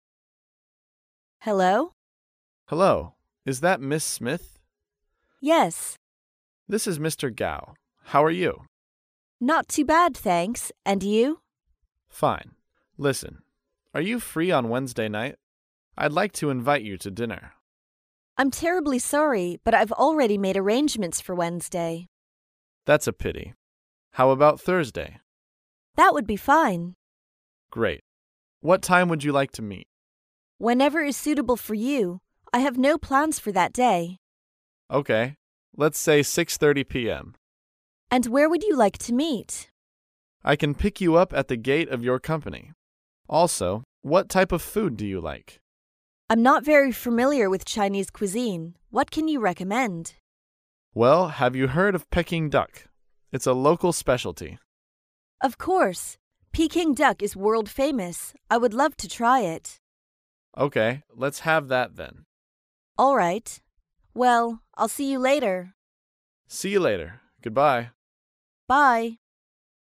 在线英语听力室高频英语口语对话 第43期:商谈邀请的听力文件下载,《高频英语口语对话》栏目包含了日常生活中经常使用的英语情景对话，是学习英语口语，能够帮助英语爱好者在听英语对话的过程中，积累英语口语习语知识，提高英语听说水平，并通过栏目中的中英文字幕和音频MP3文件，提高英语语感。